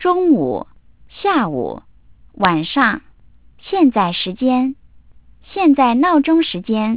- sampling rate : 8 kHz
- quantization bits : 4 bits (data rate : 32 kbps)
original decoded speech